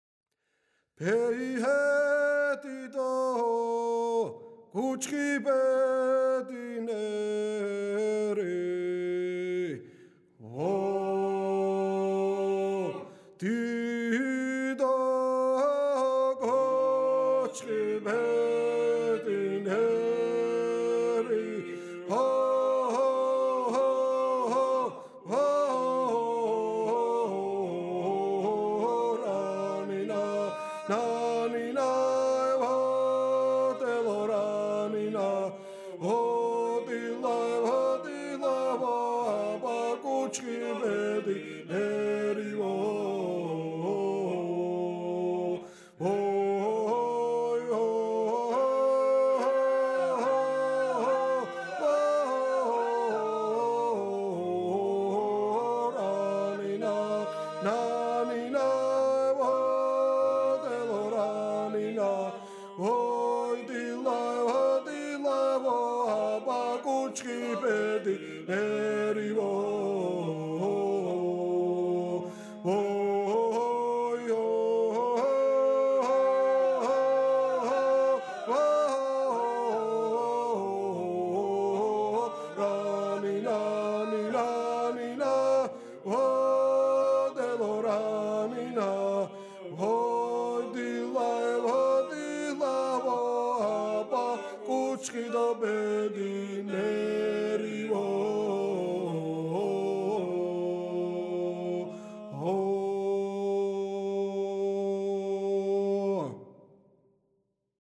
სასწავლო ჩანაწერი II ხმ